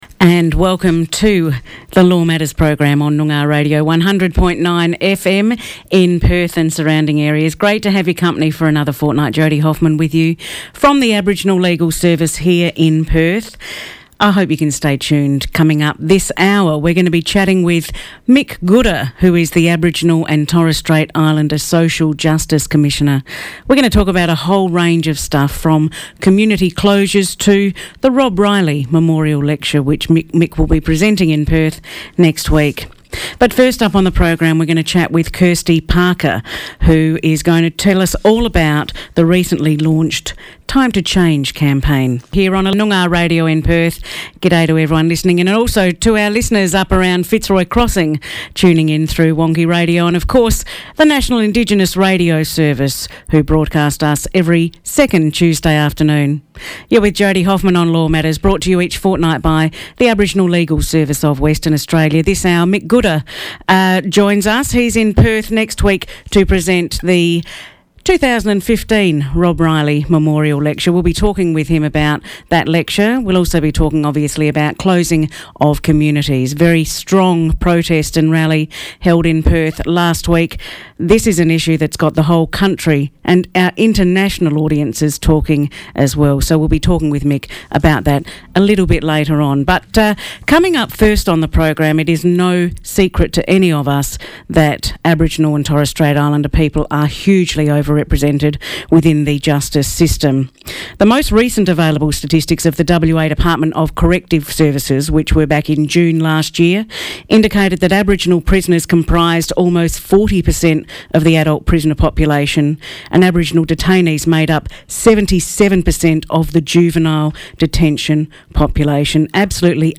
Mick Gooda, Aboriginal and Torres Strait Islander Social Justice Commissioner discusses his role with the Australian Human Rights Commission, and talks about current issues, including Community closures, funding and over-representation of our people with the criminal justice system.